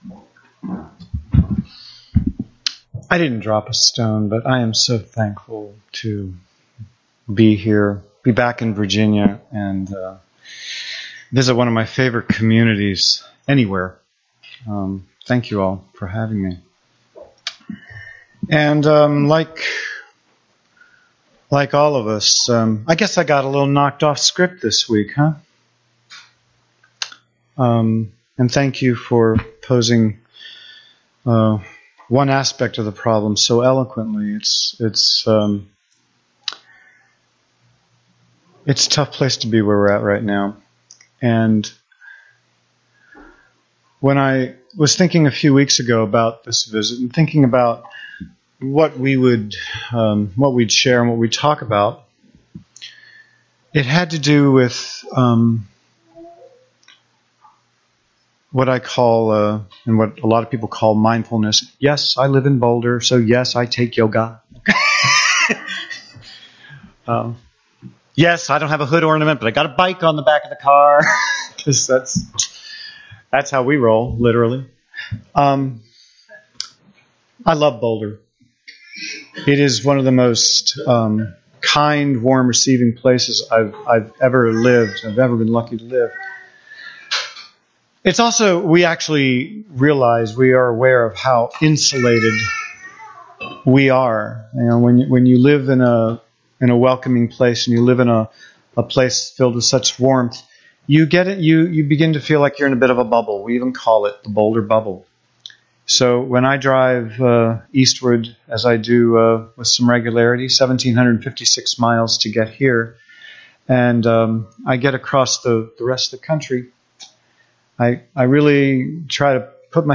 In this sermon, the speaker calls for a transition from passive empathy to meaningful personal action in response to societal hatred and negativity.